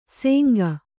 ♪ sēnga